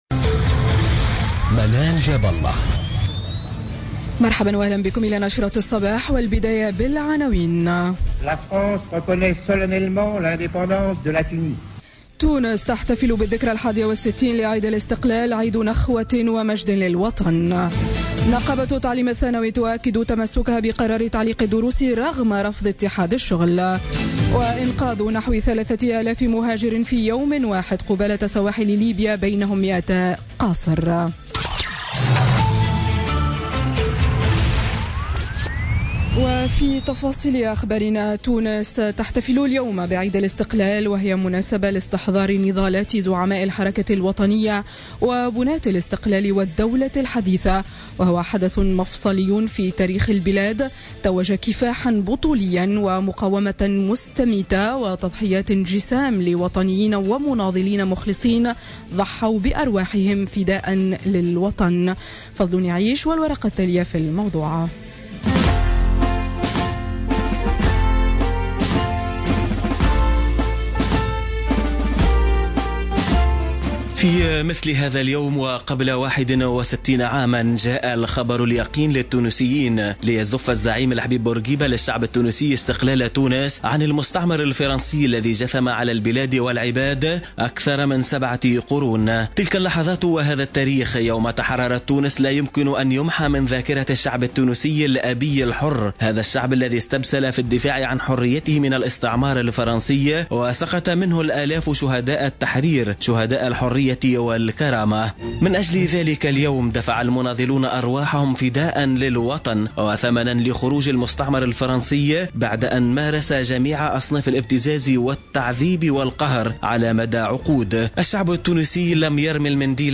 نشرة أخبار السابعة صباحا ليوم الإثنين 20 مارس 2017